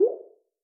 waterdrop-low.wav